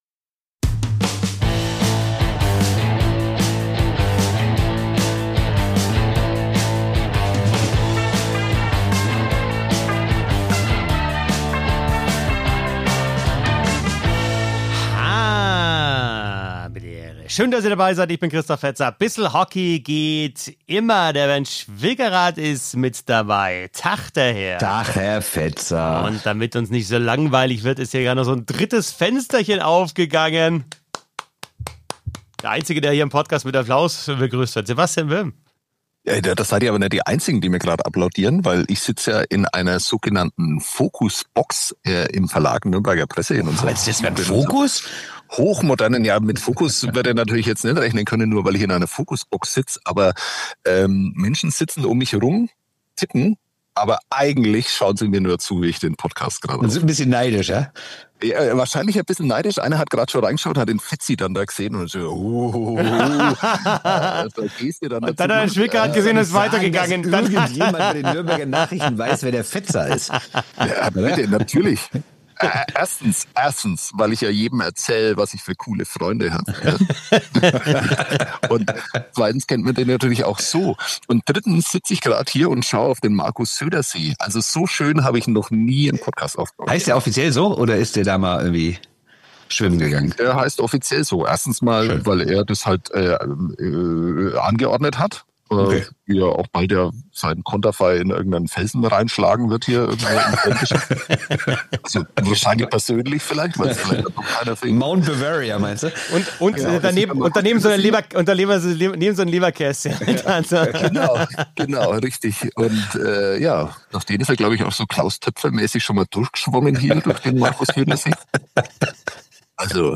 Dazu gibt es das Quiz "Wann war das nomma?"